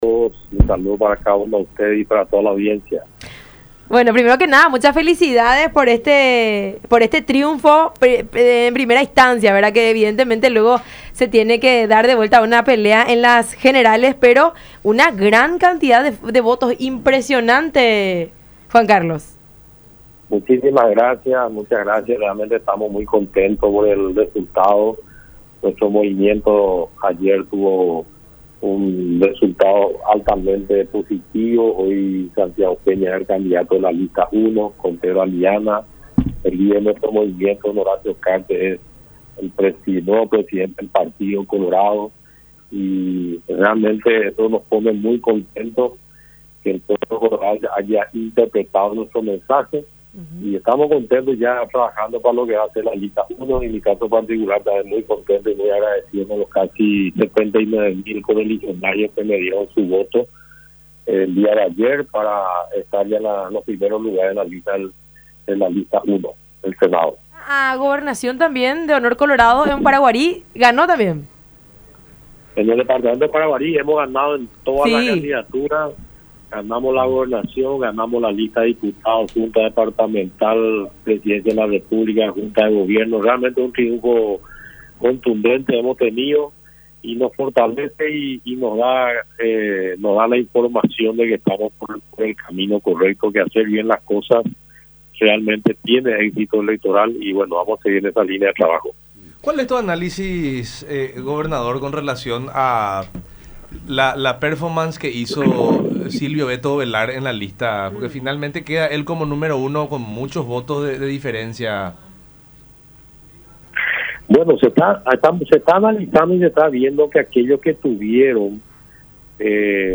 El proceso de acercamiento seguramente se dará a partir de las próximas semanas”, dijo Baruja en conversación con La Unión Hace La Fuerza a través de Unión TV y radio La Unión.